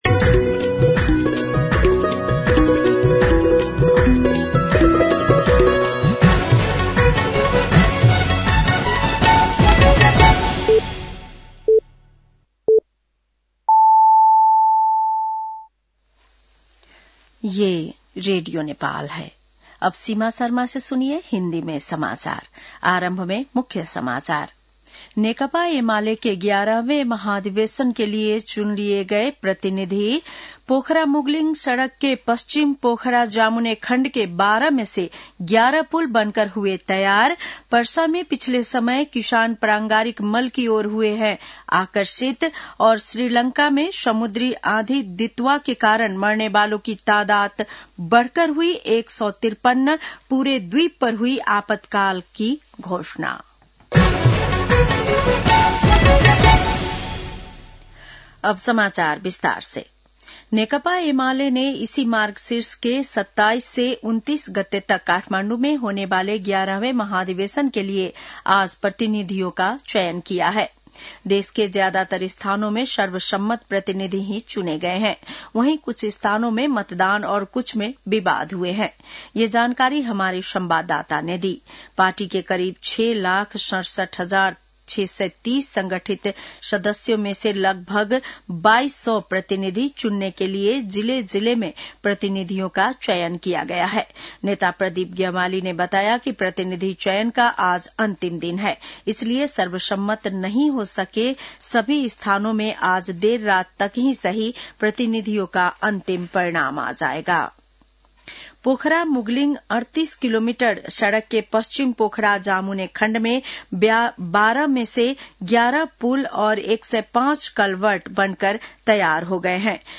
बेलुकी १० बजेको हिन्दी समाचार : १३ मंसिर , २०८२
10-pm-News.mp3